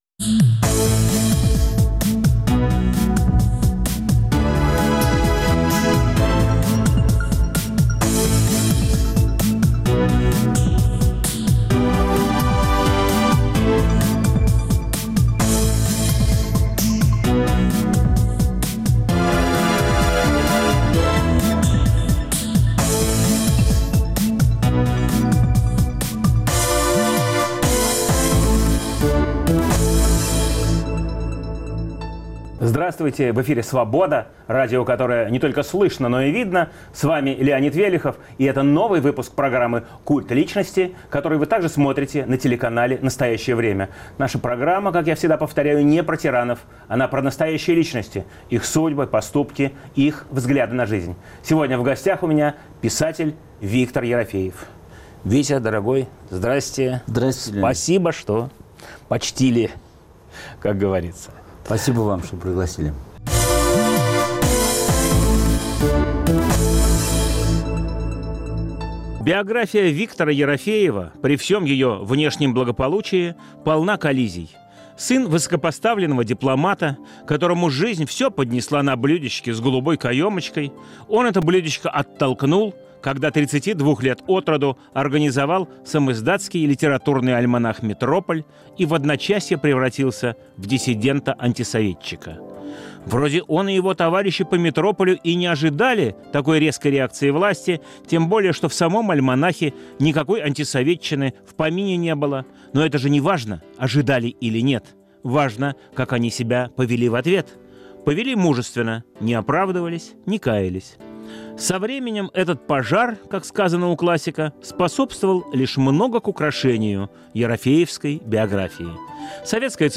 В студии нового выпуска "Культа личности" писатель Виктор Ерофеев.